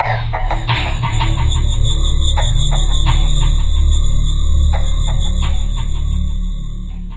节奏序列21 - 声音 - 淘声网 - 免费音效素材资源|视频游戏配乐下载
循环的声音来自于一个鼓的循环，混合了不同的经典效果，如变调、混响、延迟、均衡器等，还有一个深沉的低音合成器声音。